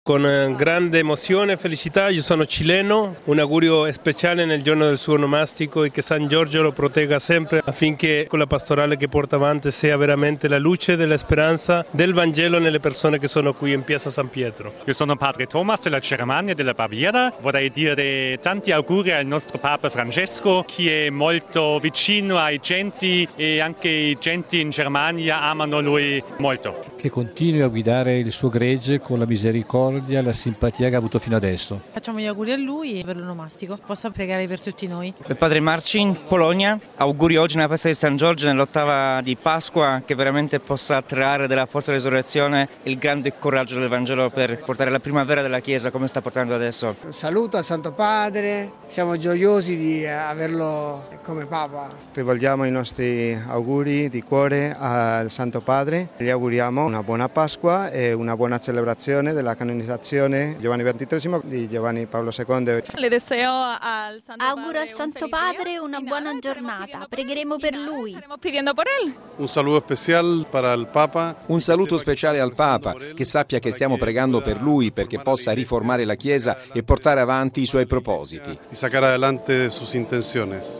In tanti, presenti in Piazza San Pietro, hanno fatto gli auguri al Pontefice.